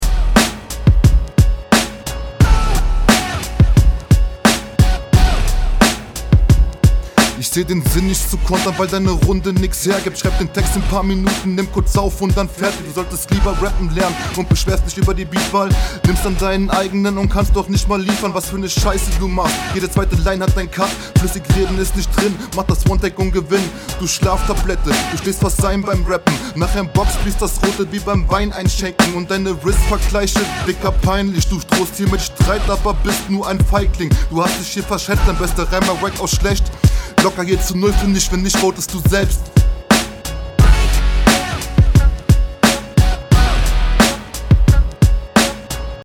Was hast du denn da im Mix gemacht?
Besser gerappt, bessere Reime, Inhalt geht klar.